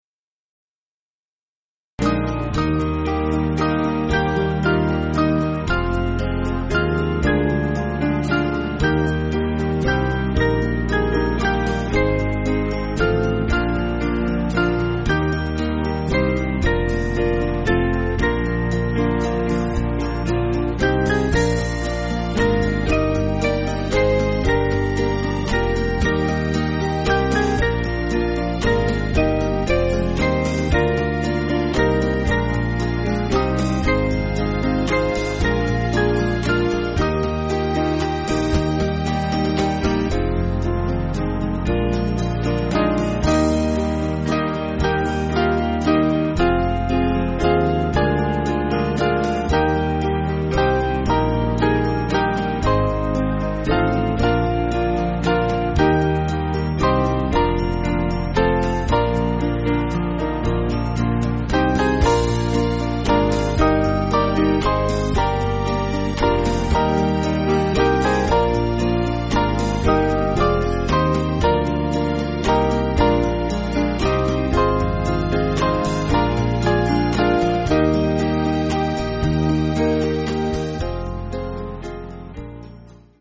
8.8.6.D
Small Band
(CM)   4/Eb